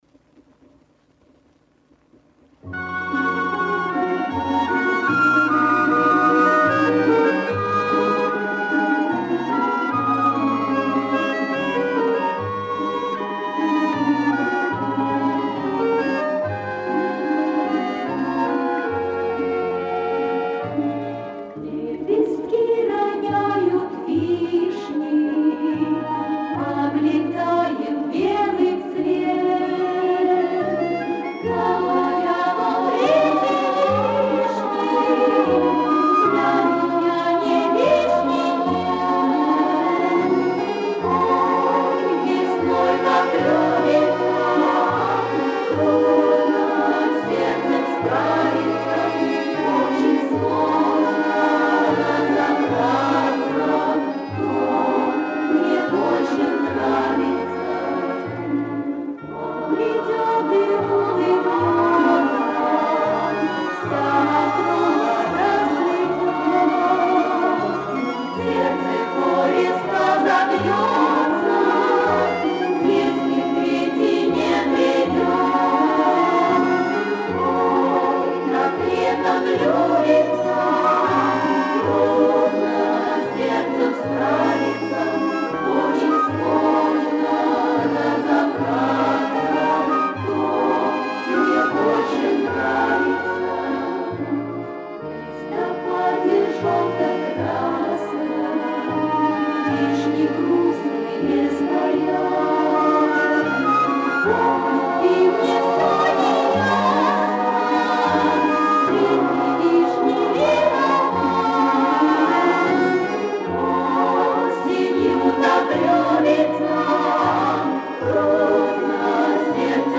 Спасибо, правда звук не очень